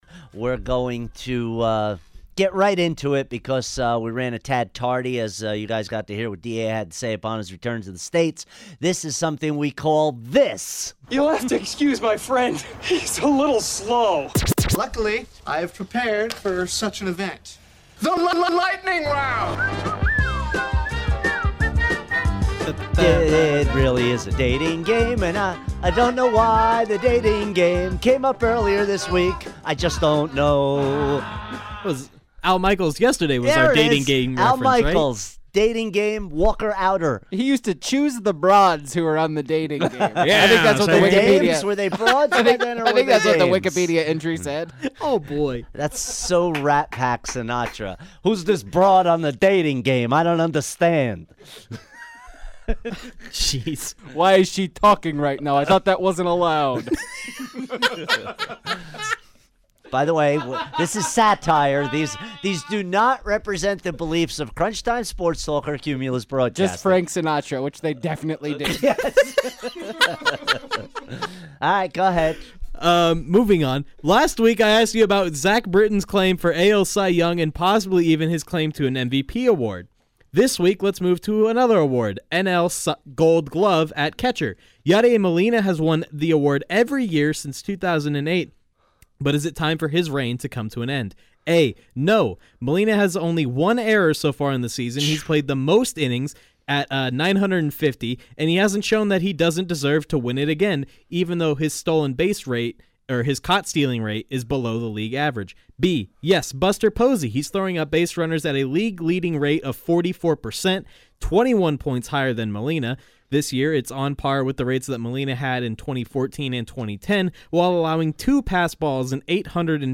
goes through the biggest sports stories of the day rapid fire style